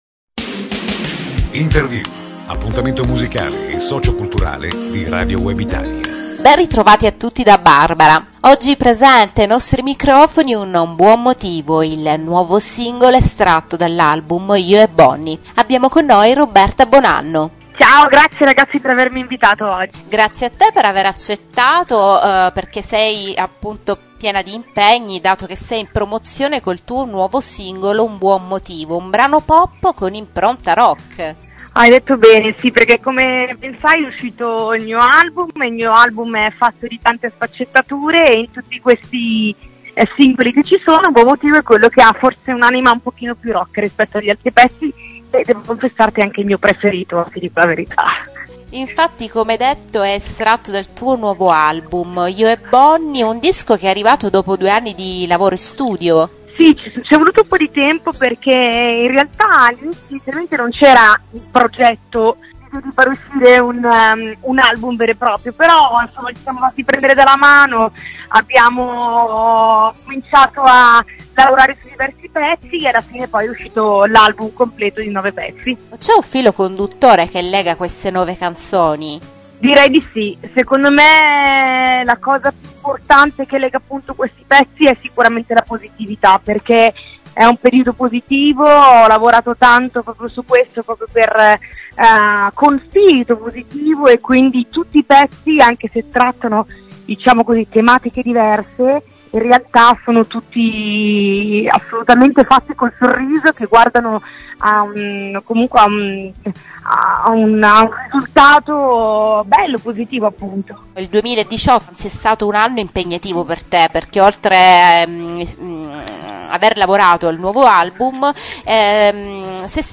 Intervista a Roberta Bonanno
roberta-bonanno-intervista.mp3